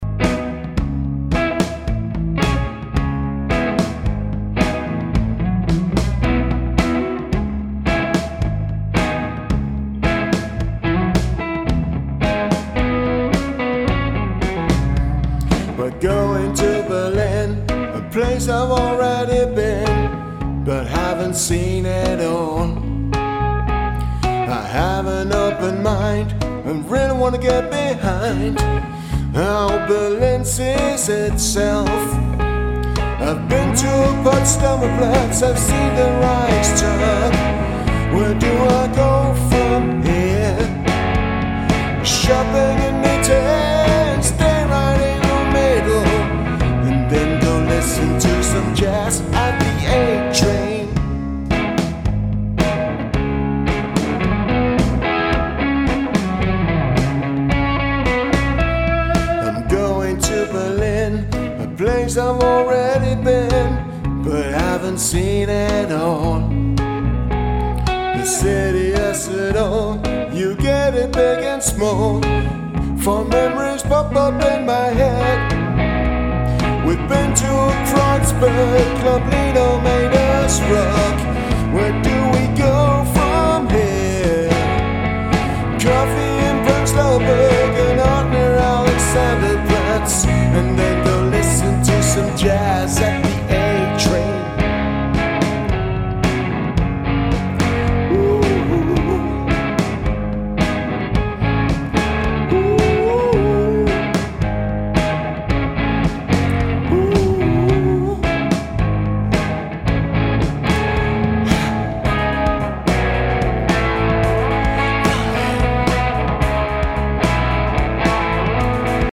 • Americana
• Rock